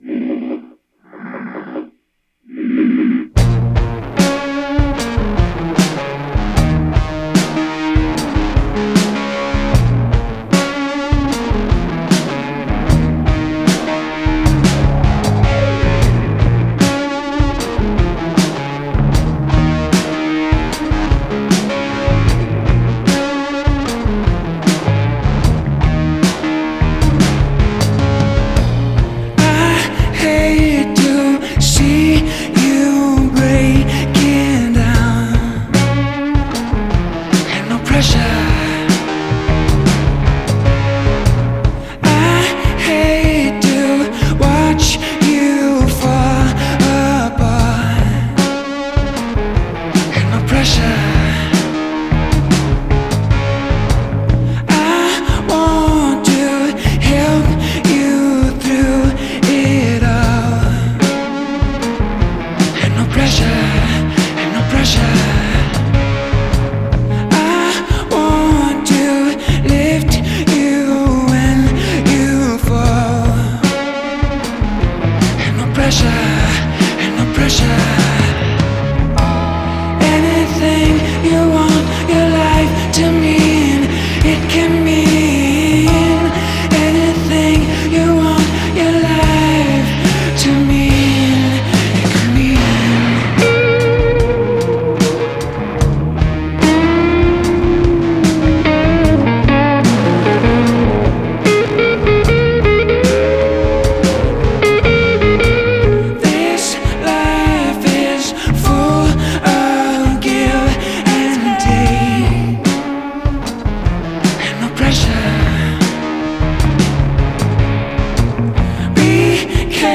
Genre: Folk Rock, Singer-Songwriter